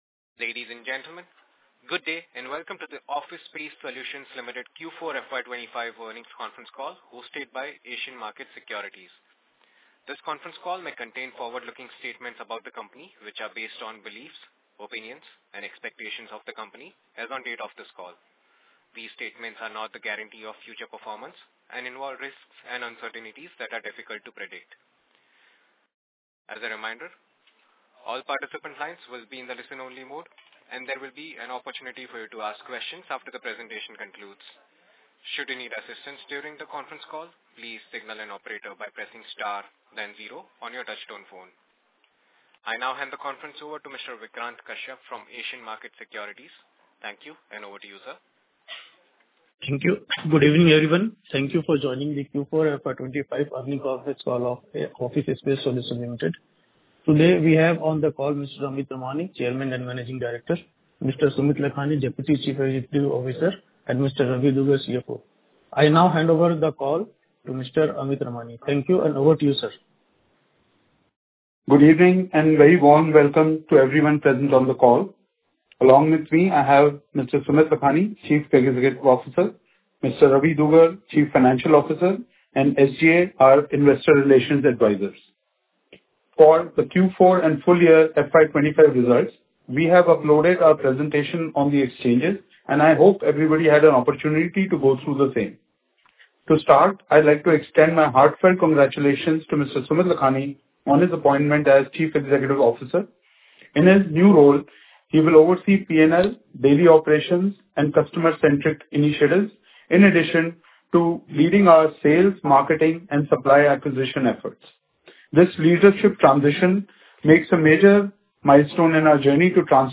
Recording Earning Call 12.02.2025